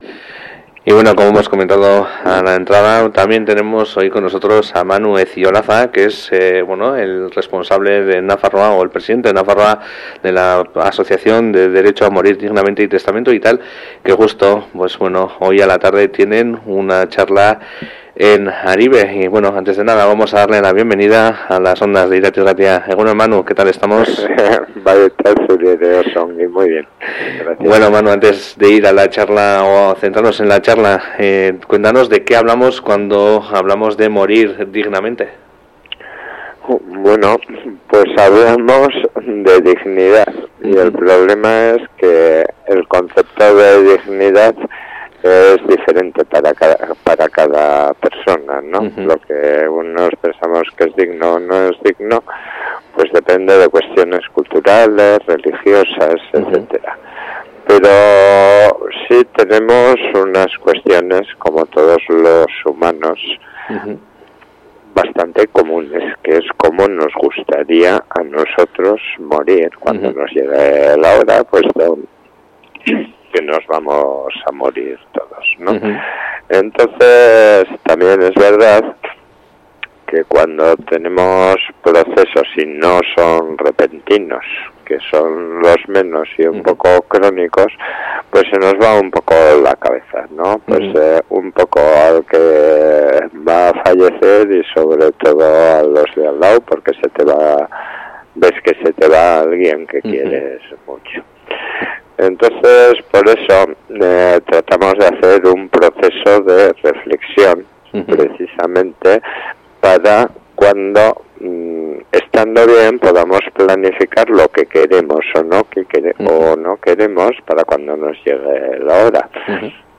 Kartela: Klikatu hemen elkarrizketa jaisteko